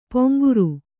♪ poŋguru